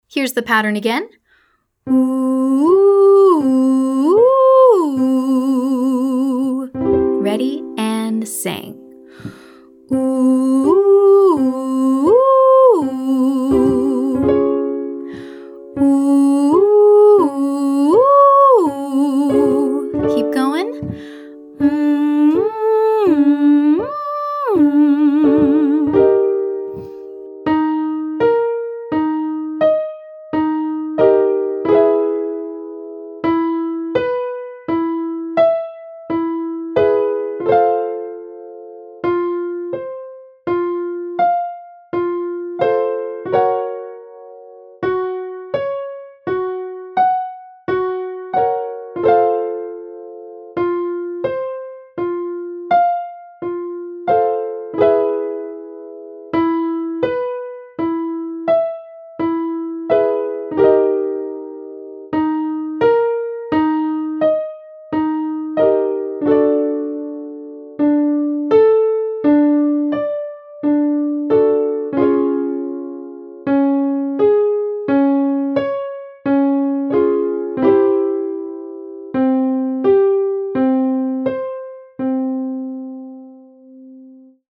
Warmup
Exercise 2: 15181 slide
Narrow vowels include OO and EE, and semi-closed exercises include lip trills, straw singing, NG, hum, VV - you choose!